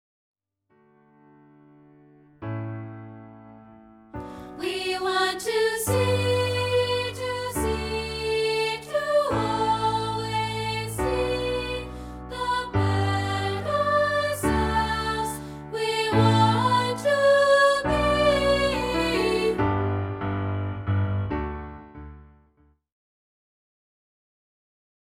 cheery song
including a rehearsal track of part 3, isolated.